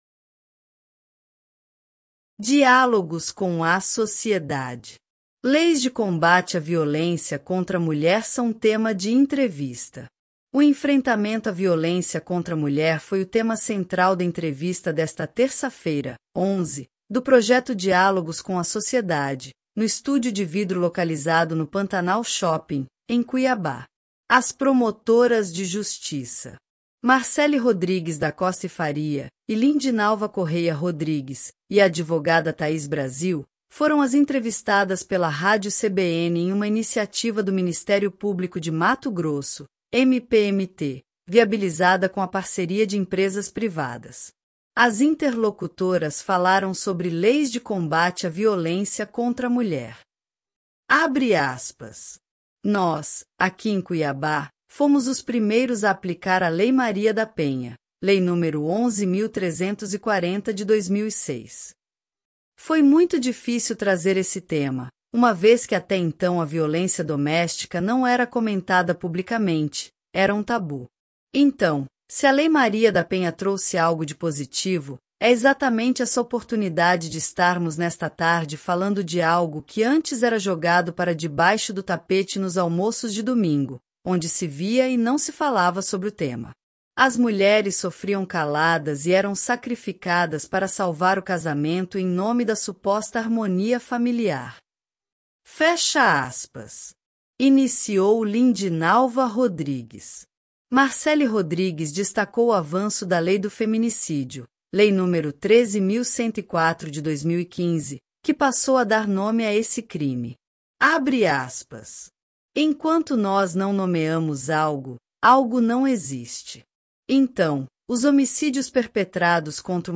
Leis de combate à violência contra a mulher são tema de entrevista
Leis de combate à violência contra a mulher são tema de entrevista‐ .mp3